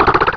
pokeemerald / sound / direct_sound_samples / cries / meditite.aif